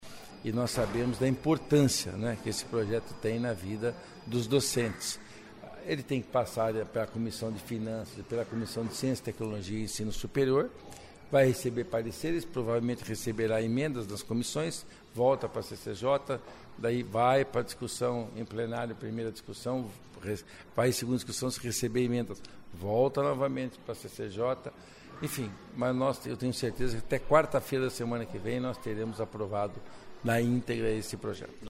Ouça entrevista do relator do projeto que regulamenta o TIDE para professores do ensino superior das universidades estaduais do Paraná, deputado Luiz Cláudio Romanelli (PSB), onde ele explica como será a tramitação da proposta, que foi aprovada na manhã desta quinta-feira (28) em uma reunião extraordinária da CCJ.
(Sonora)